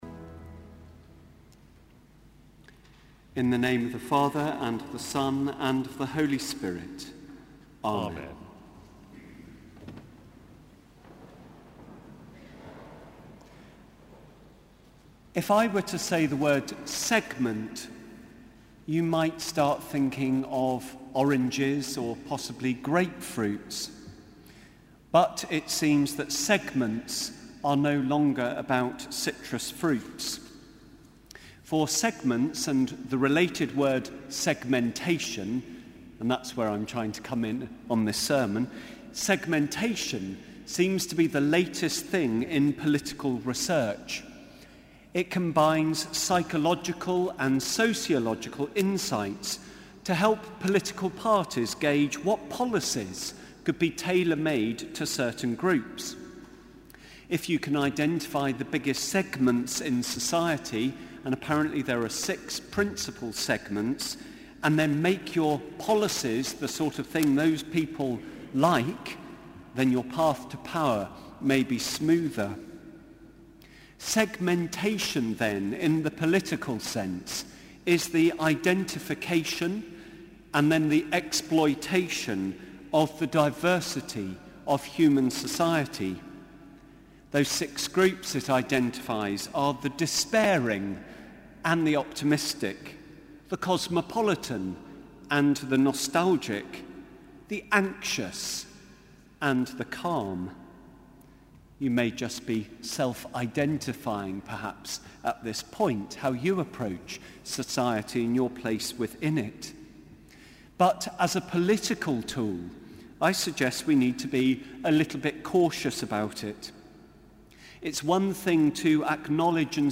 Sermon: Evensong - 30 March 2014